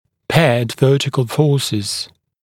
[‘peəd ‘vɜːtɪkl fɔːsɪz][‘пэад ‘вё:тикл ‘фо:сиз]парные вертикальные силы